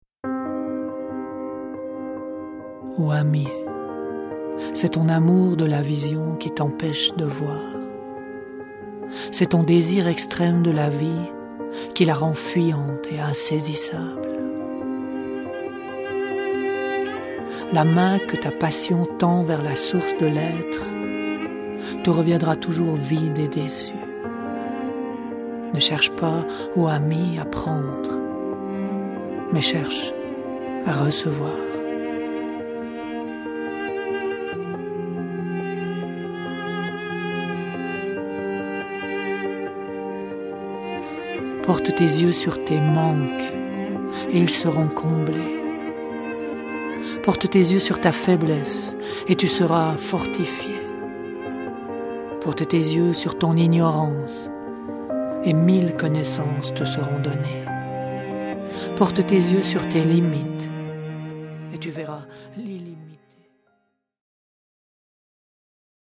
Au travers de ces poèmes accompagnés de musiques inspirantes, nous souhaitons vous offrir des moments d’élévation, de ressourcement et de joie profonde.